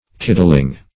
Kidling \Kid"ling\, n.